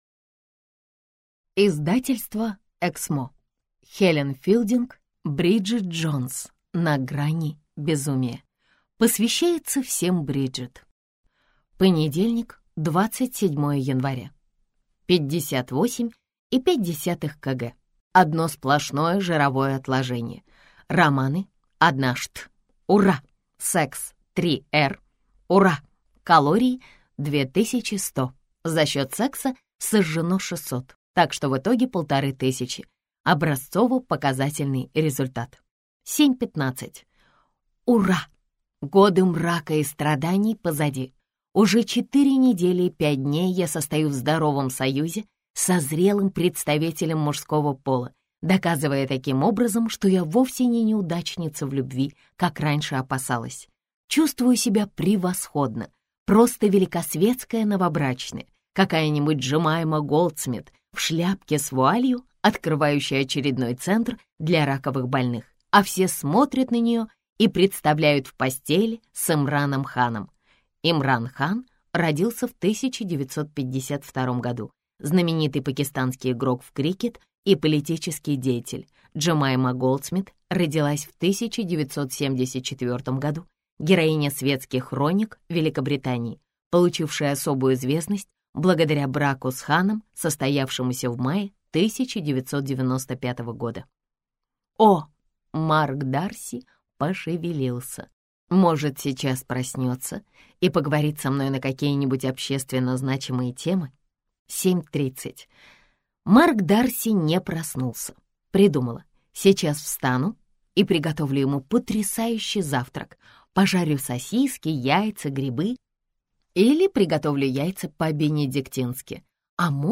Аудиокнига Бриджит Джонс: на грани безумия - купить, скачать и слушать онлайн | КнигоПоиск